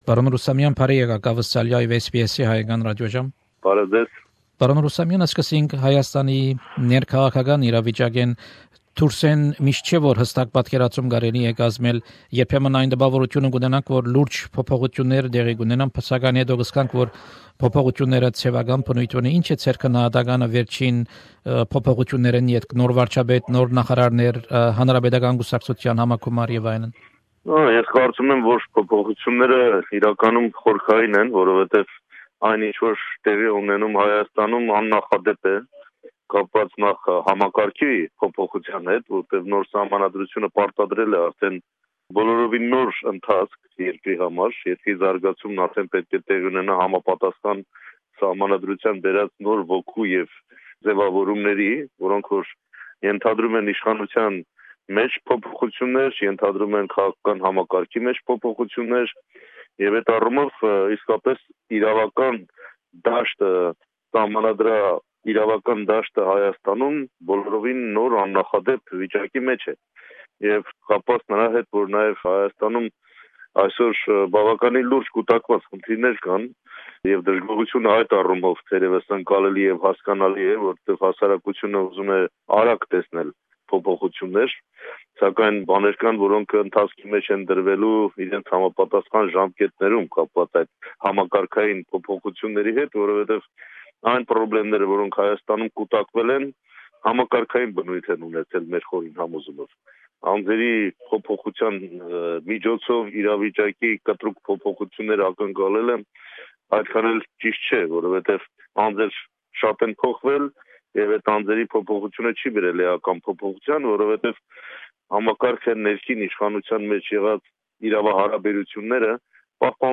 Interview with Armen Rustamyan, Head of ARF faction in the Armenian National Assembly. Mr Rustamyan is currently visiting Australia.